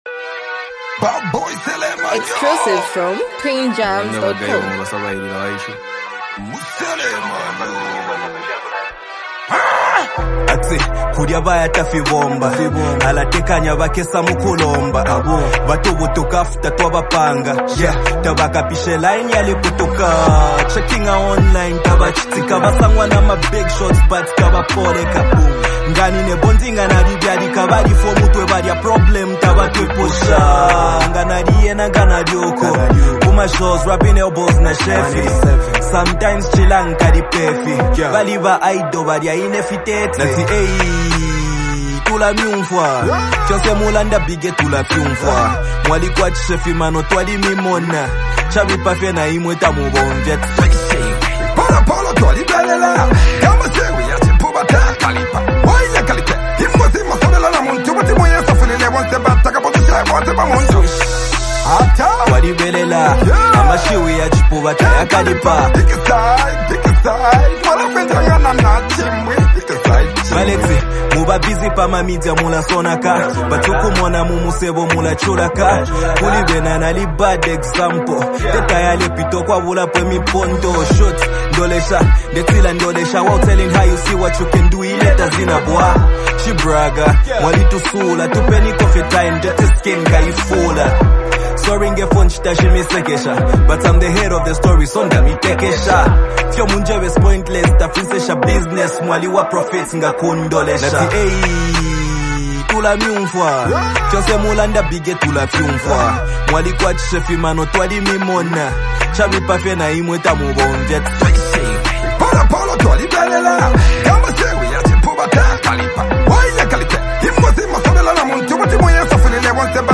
emotional and relatable song